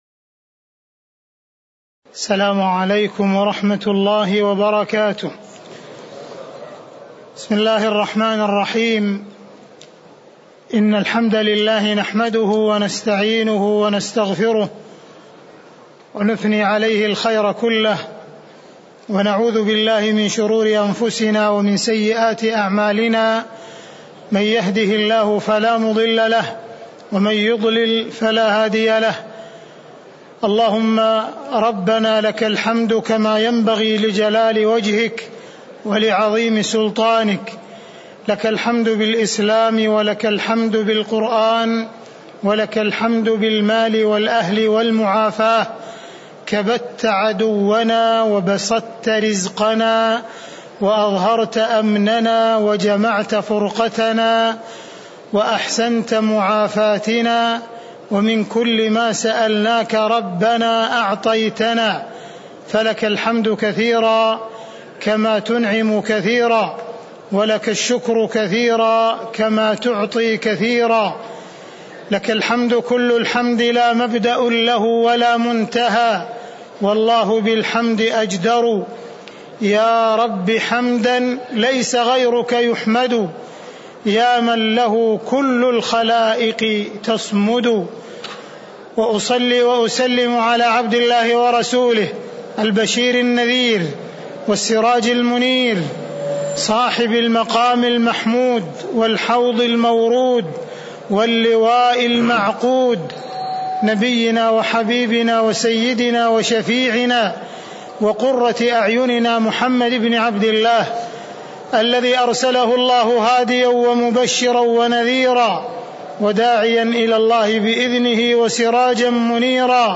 تاريخ النشر ٢١ رجب ١٤٣٨ هـ المكان: المسجد النبوي الشيخ: معالي الشيخ أ.د. عبدالرحمن بن عبدالعزيز السديس معالي الشيخ أ.د. عبدالرحمن بن عبدالعزيز السديس وقوله: وإذا كانت سعادةُ العبد في الدارين (020)